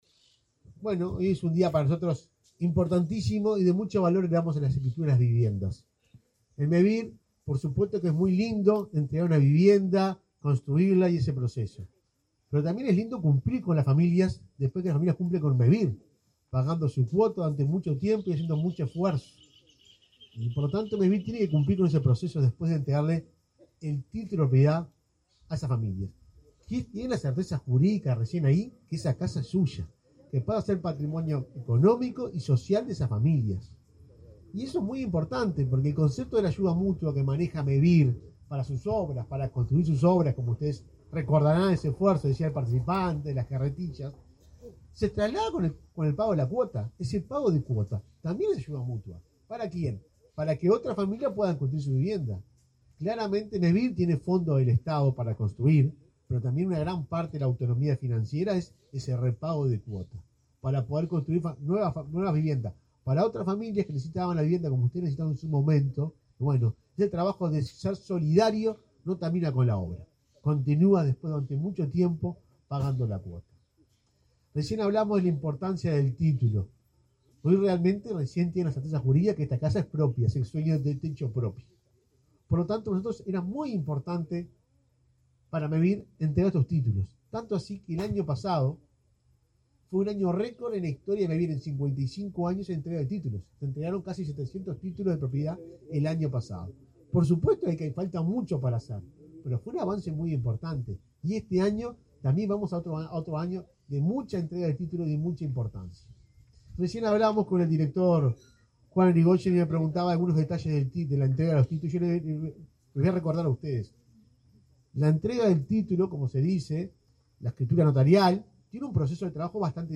Palabras del presidente de Mevir
El presidente de Mevir, Juan Pablo Delgado, presidió el acto de escritura colectiva de 25 viviendas en la localidad de San Luis al Medio, departamento